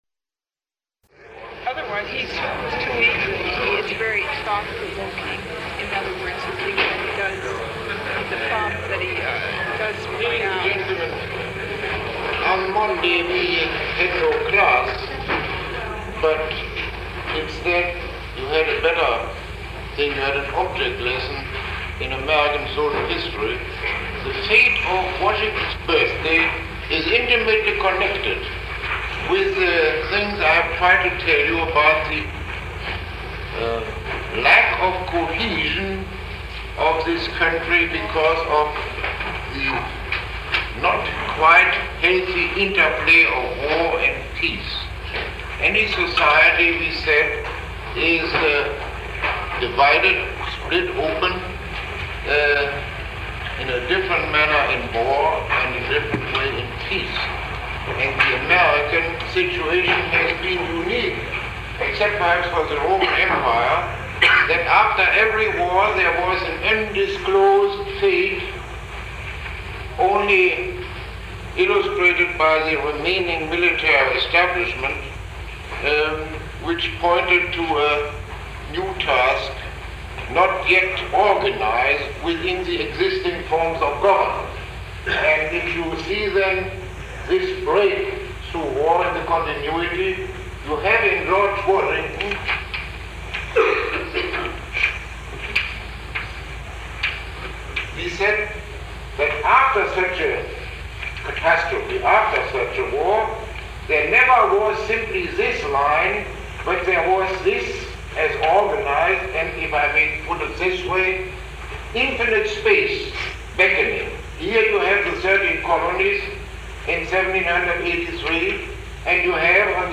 Lecture 07